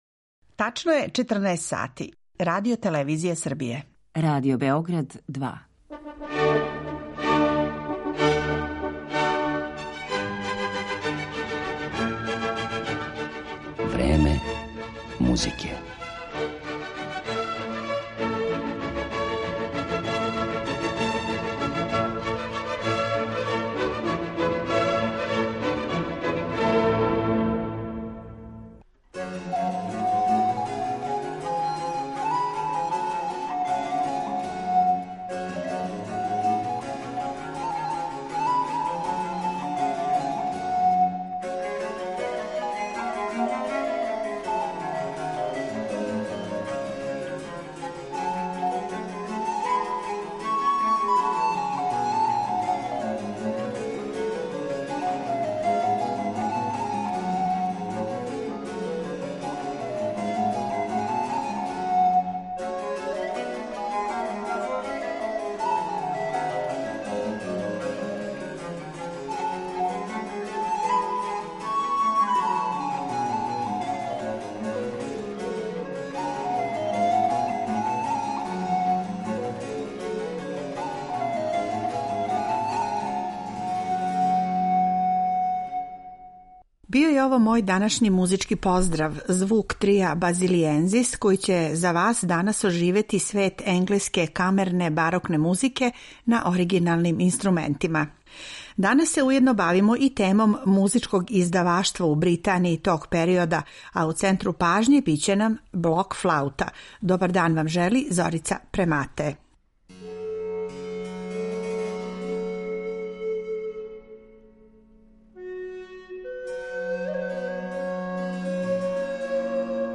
Барокна флаута